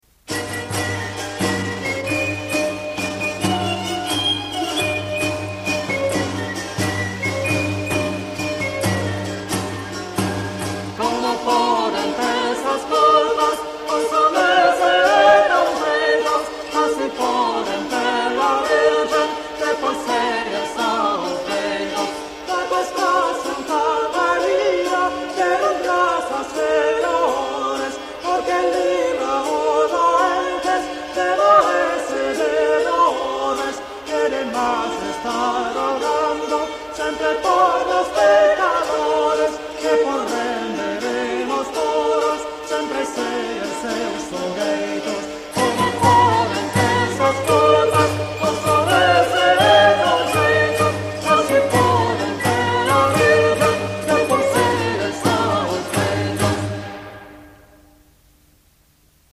– cantigas: Como poden per sas culpas